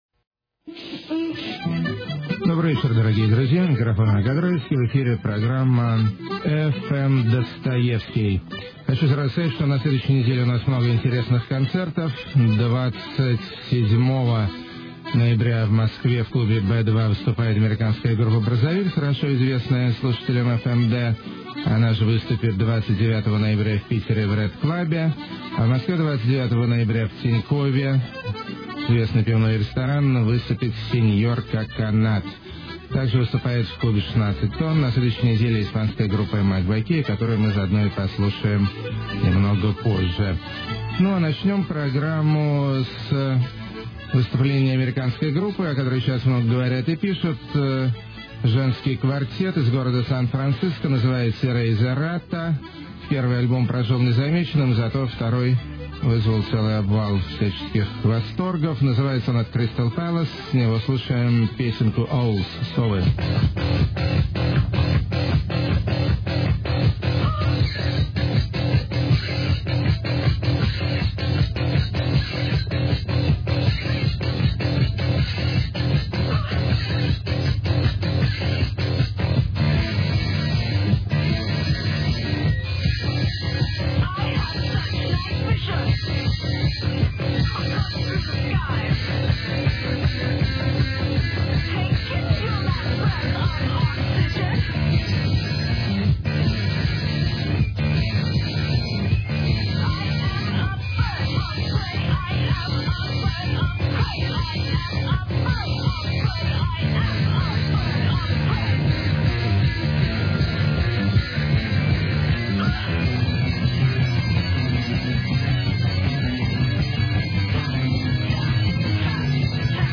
girls screaming loud
poetry meets dark music
pop samba fun
naive psychedelique
beautifully gloomy
haunted weirdobilly
lo-fi radio fantasies
brutal noise propaganda
electrosurf